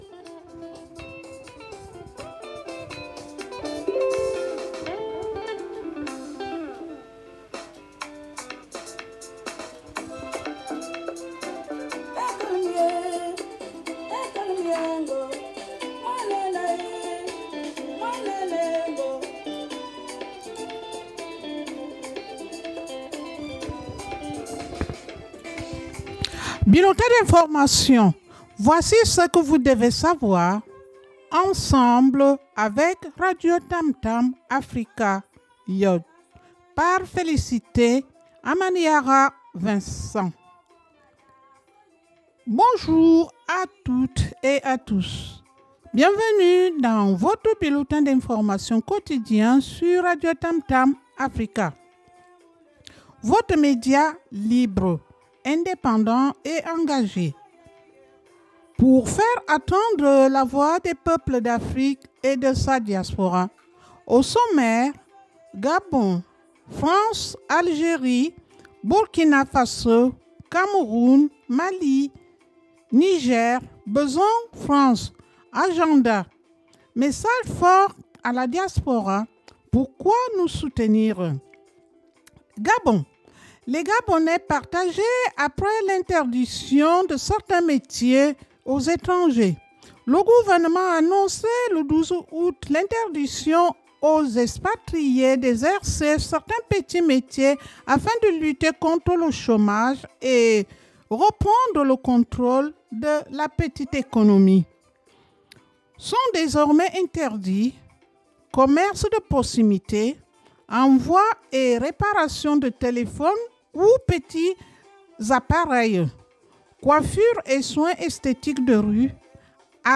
BULLETIN D’INFORMATION